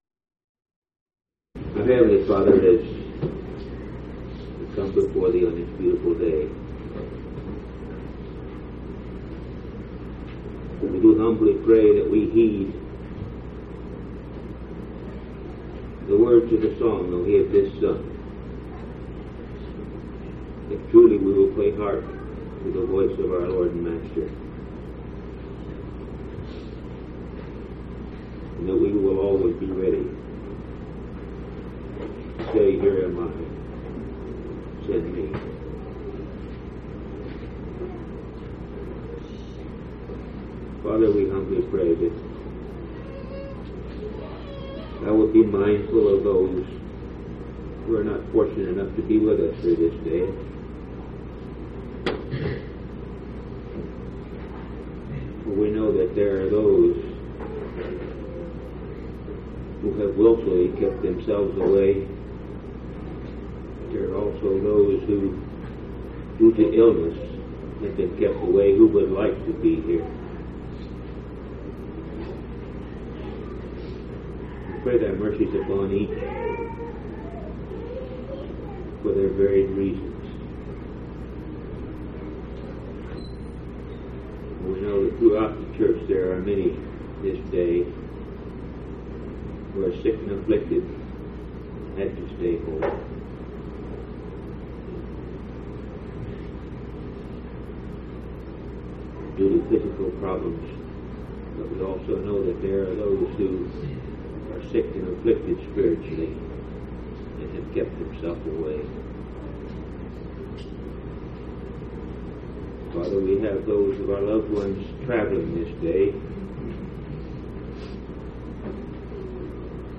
9/8/1985 Location: Phoenix Local Event